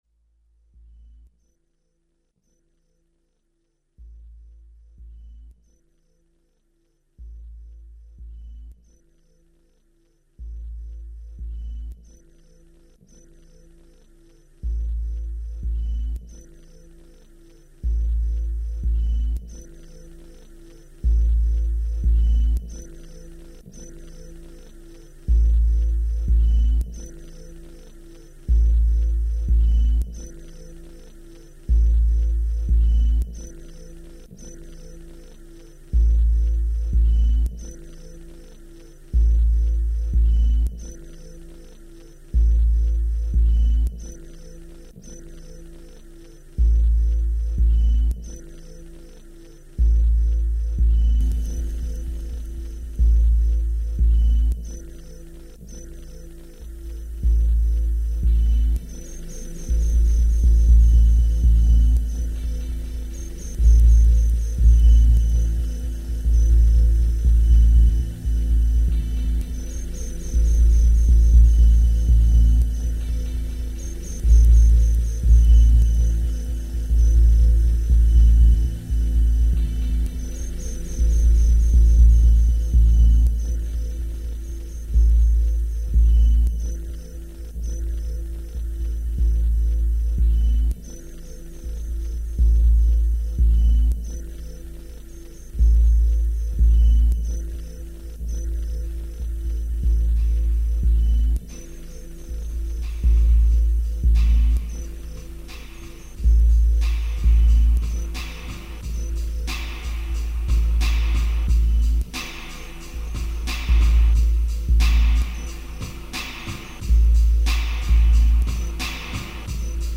File under: Minimal Electronica / Illbient